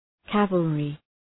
Προφορά
{‘kævəlrı}